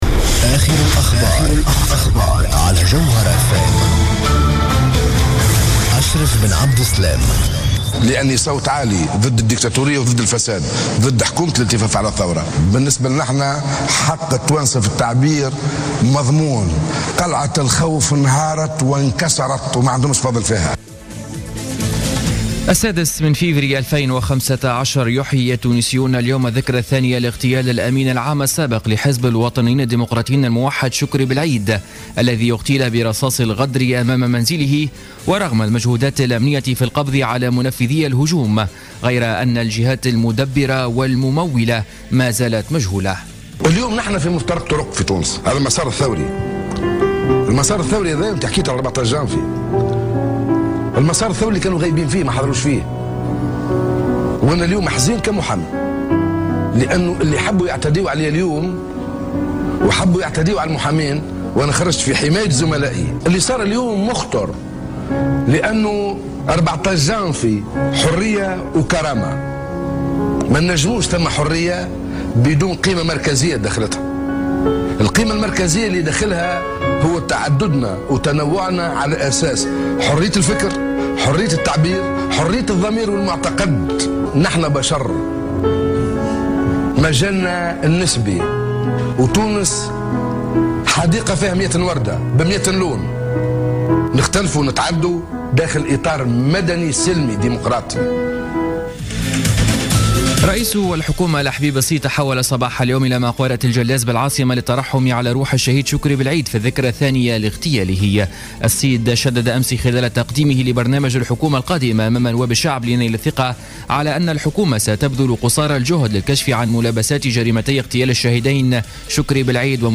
نشرة أخبار منتصف النهار ليوم الجمعة 06-02-15